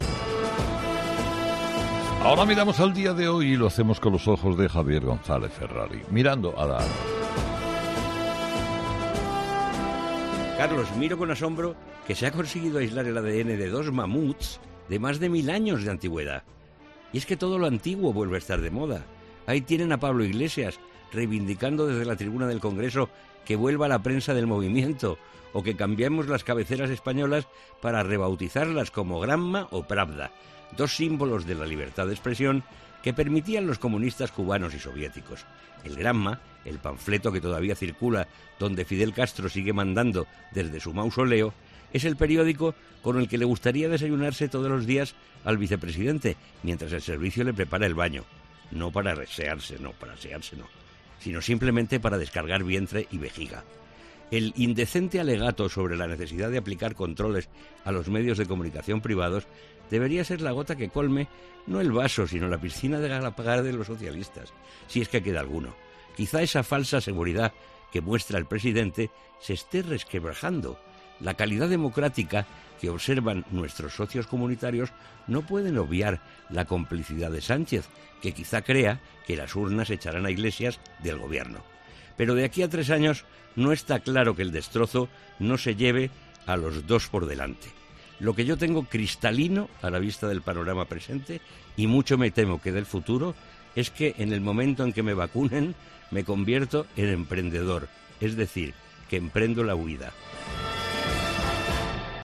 El periodista opina en 'Herrera en COPE' sobre el control de los medios que pretende ejercer el vicepresidente segundo del Gobierno